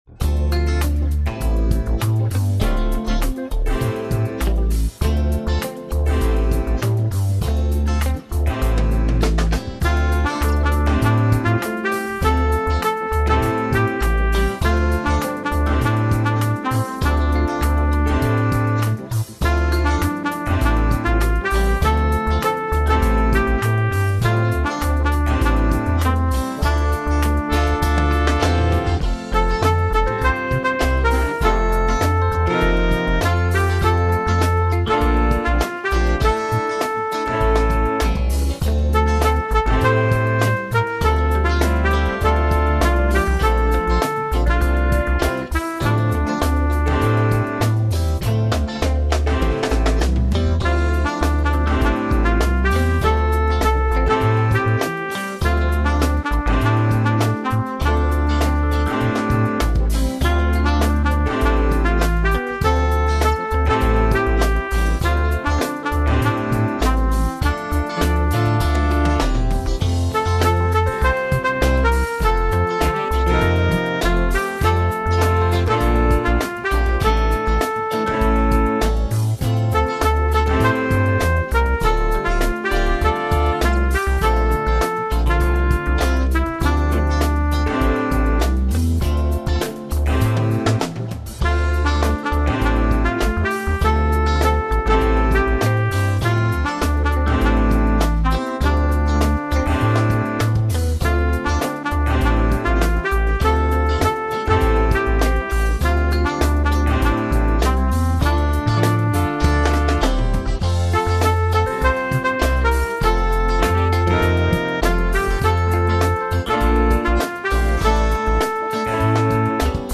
I went the funk route for my version.